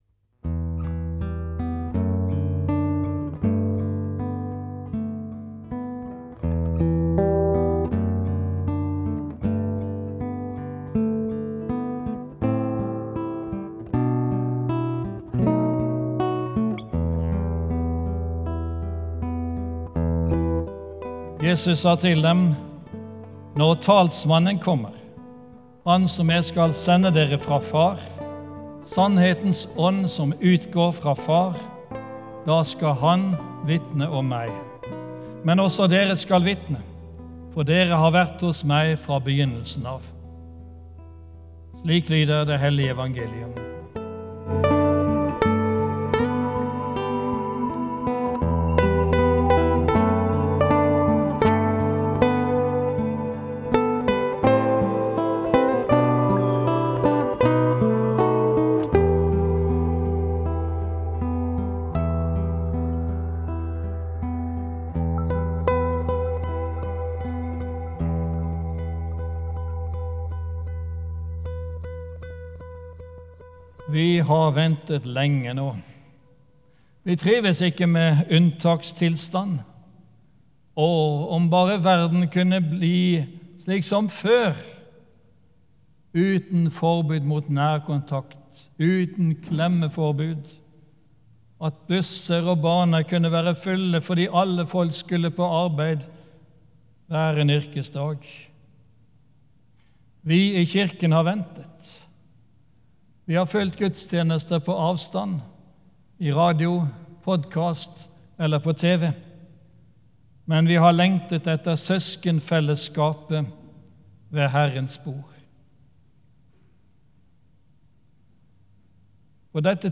Hør søndagens preken
Her kan du høre søndagens preken fra 24 mai på podcast.
podcast ep13 - gudstjeneste fra 24. mai.mp3